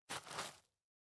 Illusion-UE5/Dirt Walk - 0006 - Audio - Dirt Walk 06.ogg at be5b88d38c70e6c6b6adaed16e054f160d0c1cd8